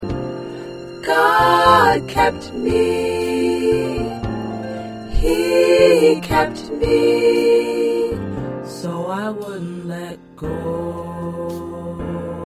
Practice tracks